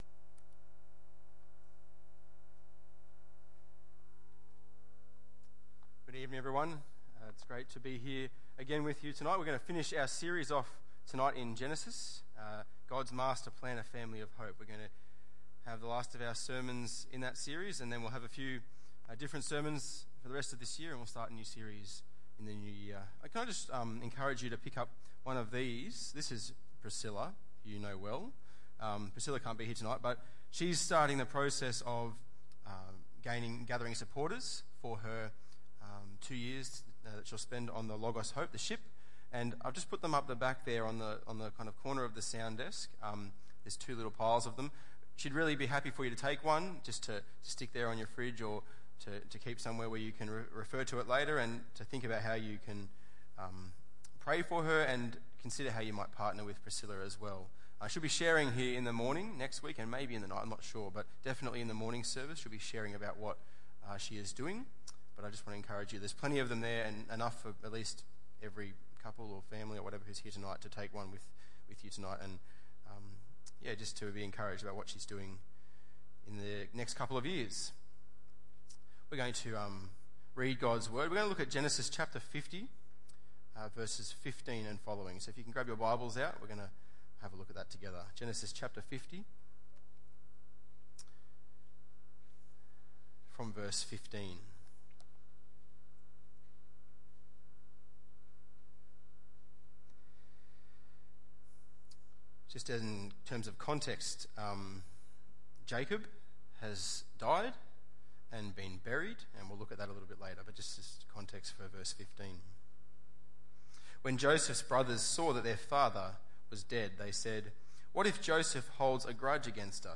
Genesis 50:15-26 Tagged with Sunday Evening